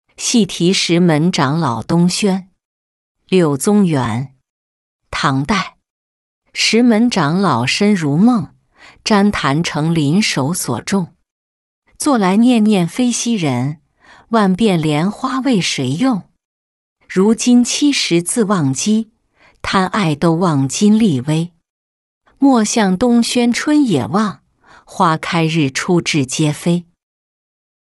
戏题石门长老东轩-音频朗读